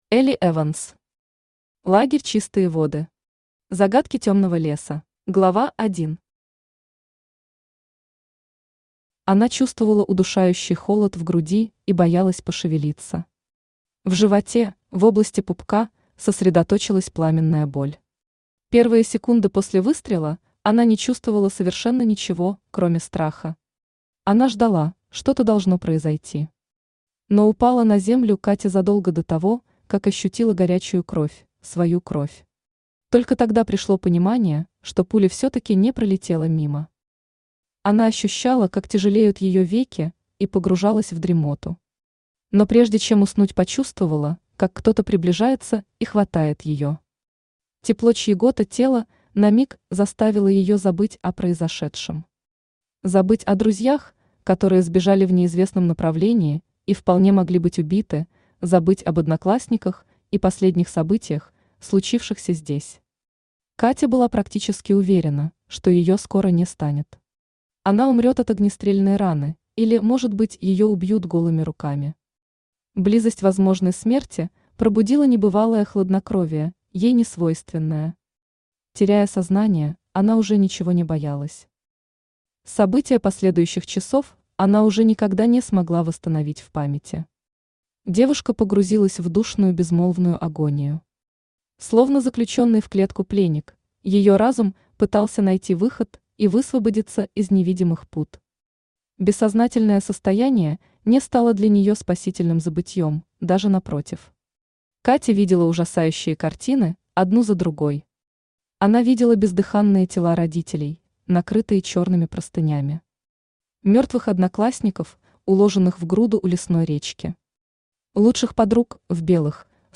Аудиокнига Лагерь «Чистые воды». Загадки тёмного леса | Библиотека аудиокниг
Загадки тёмного леса Автор Элли Эванс Читает аудиокнигу Авточтец ЛитРес.